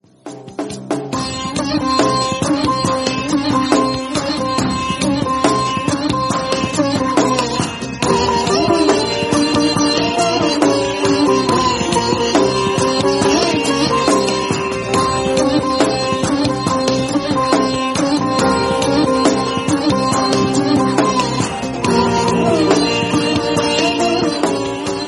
enjoy a spiritual vibe every time your phone rings.